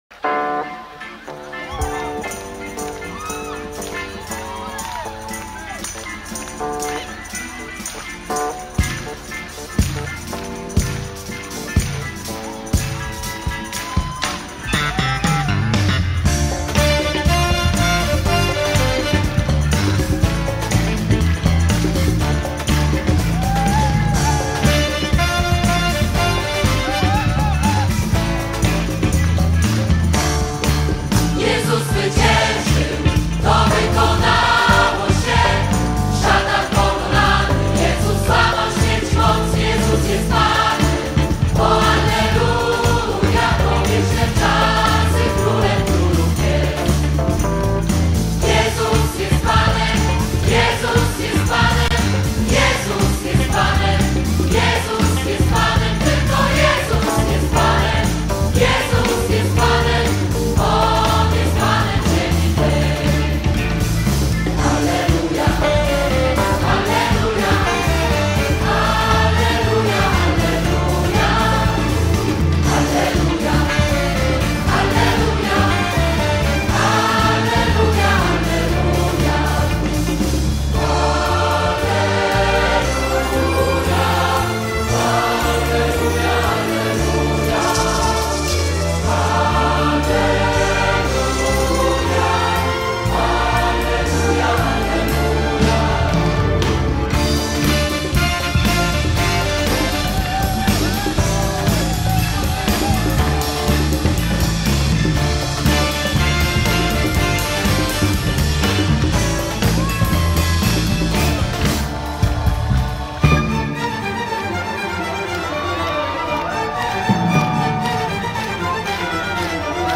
Rzeszów (Live)